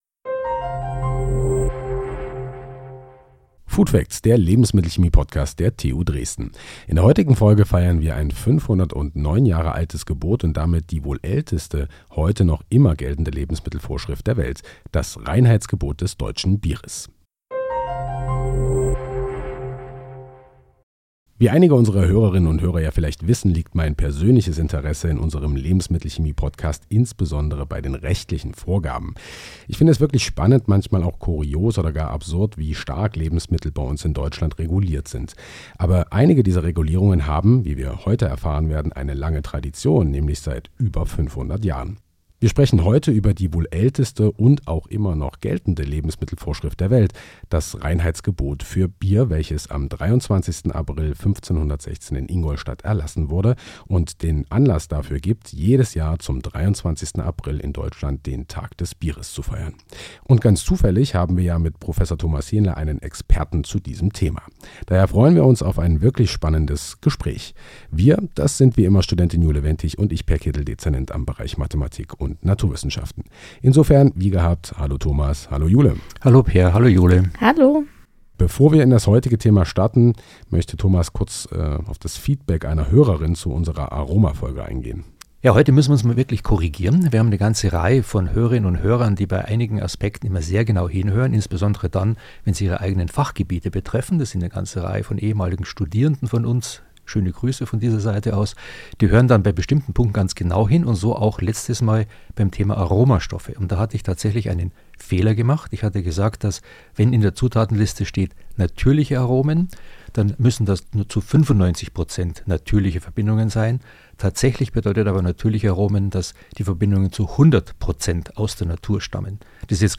Ein spannendes Gespräch mit vielen Fakten rund um das beliebteste alkoholische Getränk in Deutschland.